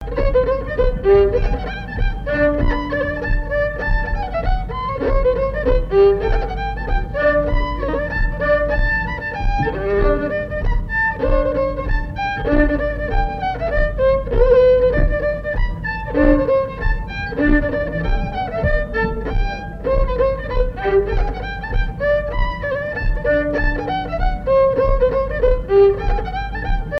Mémoires et Patrimoines vivants - RaddO est une base de données d'archives iconographiques et sonores.
danse : pas des patineurs
Assises du Folklore
Pièce musicale inédite